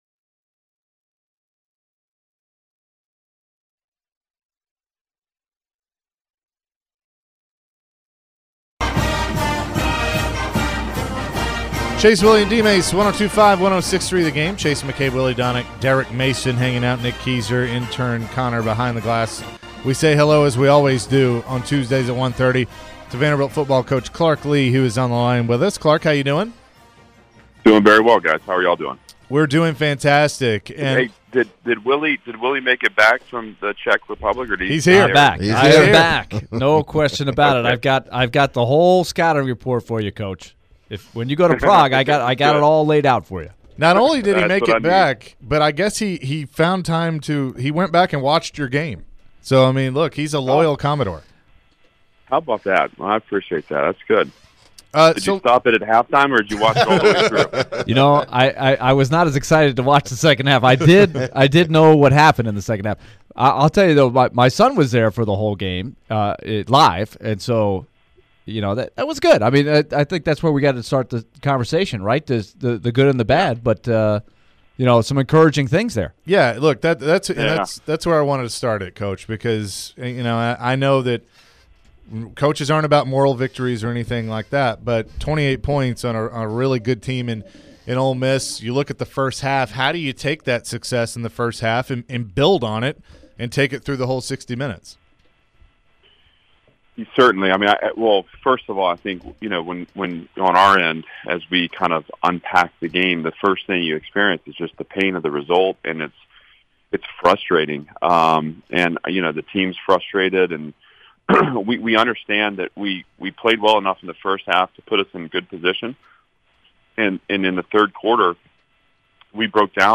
Clark Lea Full Interview (10-11-22)